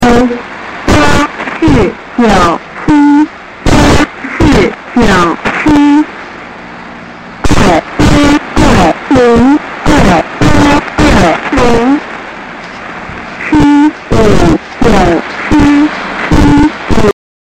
请问这是台湾的星星广播电台吗？几个星期前录得的，由于周围很吵所以录得不是很清晰。收音机是德生PL-600
口音聽得出可能來自寶島,只是兩岸現在朝向合作互信方向前進,不明白為何現在還在搞這個,